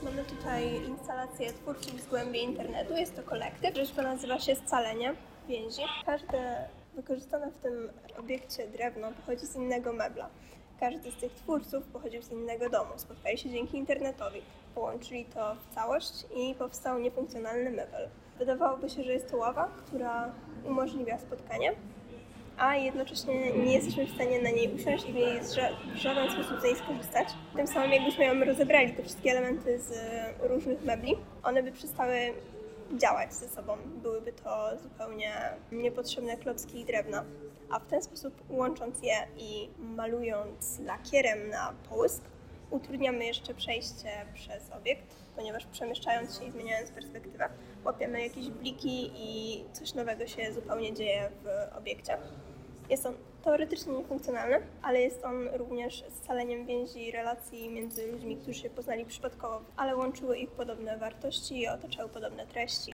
Moving Image. Intervention: Audioguide in Polish